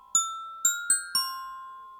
Gliding_parrot.ogg